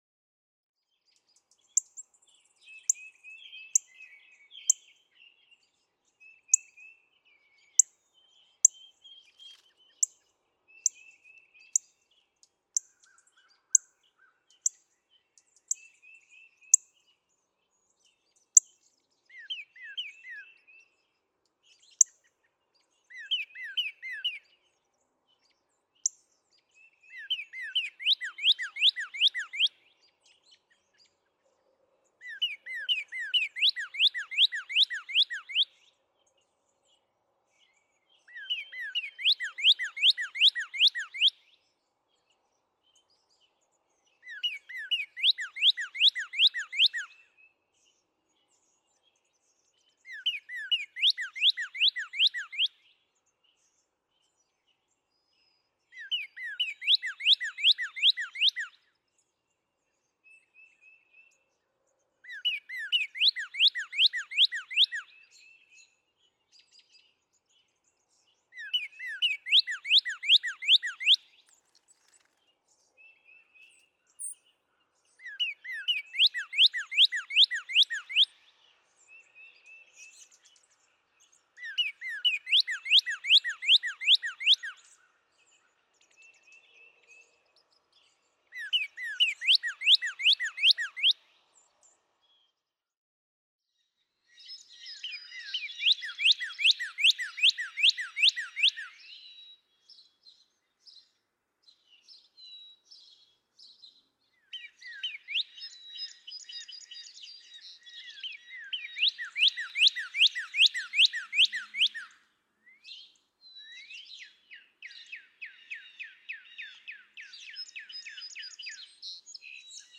Northern cardinal
♫57. As this female leaves the night roost, she initially calls with sharp chip notes, and then sings (first at 0:19). Beginning at 1:33, I focus the microphone on her mate, who is using the same song that the female is singing; they match each other's songs by choosing appropriately from their shared repertoire of a dozen or so different songs. April 9, 2017. Hatfield, Massachusetts. (2:27)
057_Northern_Cardinal.mp3